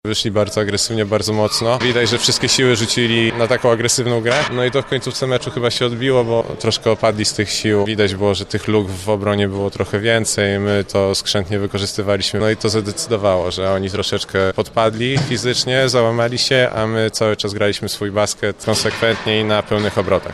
-wyjaśnia koszykarz AZS UMCS Lublin